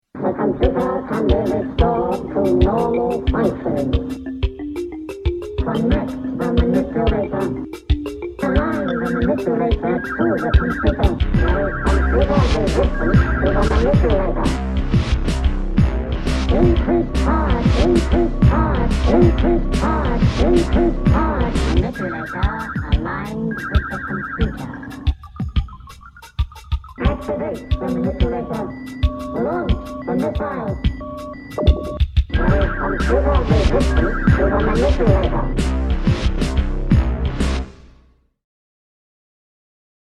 was made using an EMU Emulator III to place samples